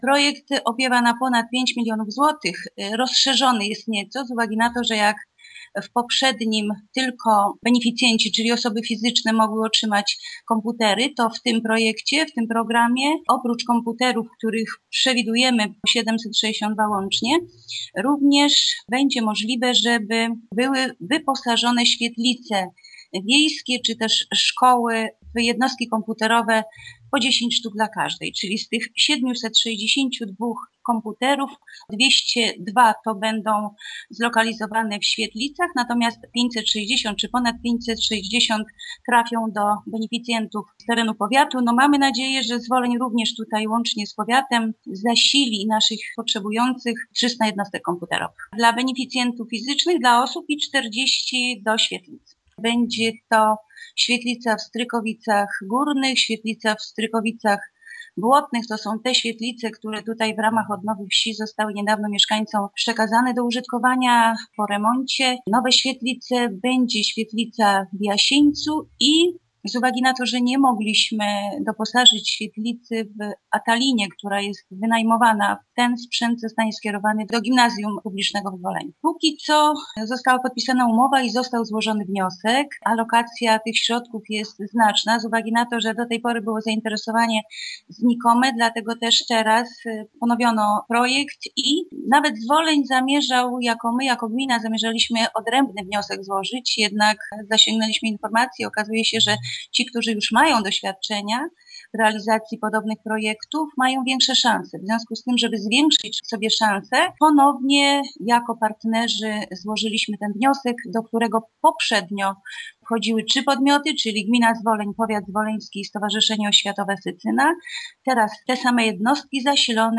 Teraz inicjatywa ma zdecydowanie szerszy zasięg i korzyści dla naszych mieszkańców powinny być większe - mówi Informacyjnej Agencji Samorządowej burmistrz Zwolenia Bogusława Jaworska: